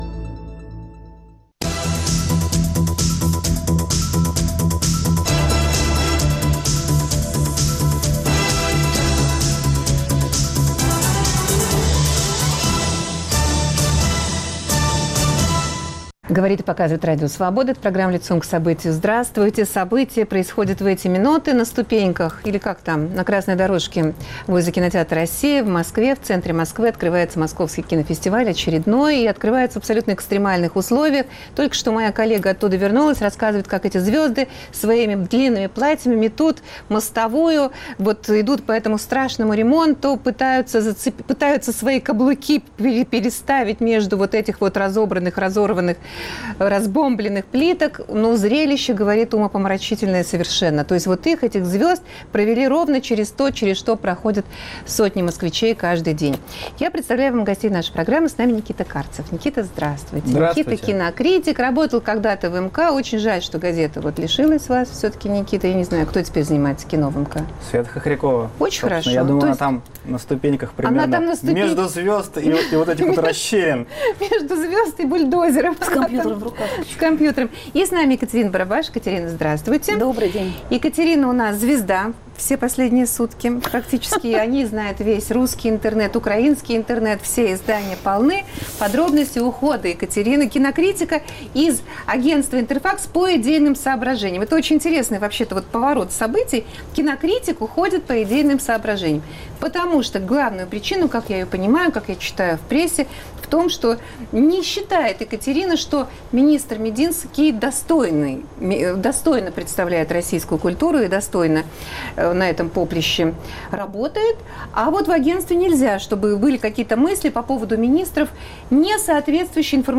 Что за кино увидят зрители на Московском международном кинофестивале и какова вероятность, что Россия на нем победит? Обсуждают независимый кинокритик